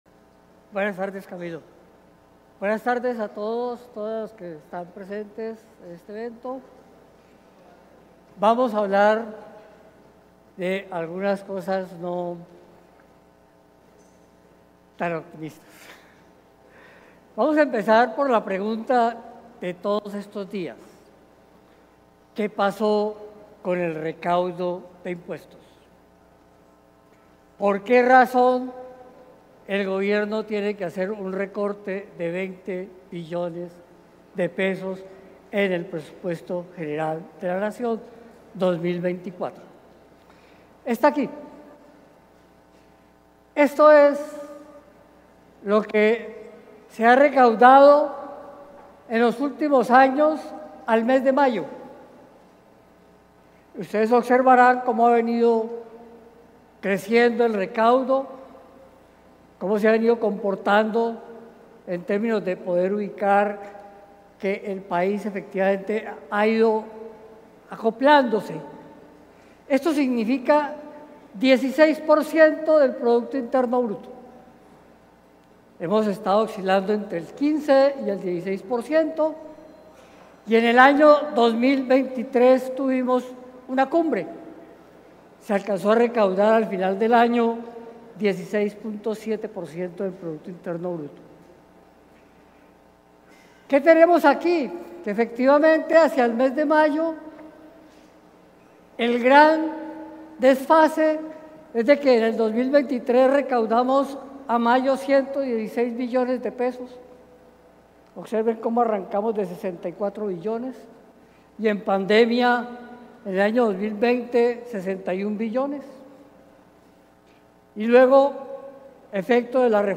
junio-26-intervencion-del-ministro-de-hacienda-en-el-26-congreso-andesco-1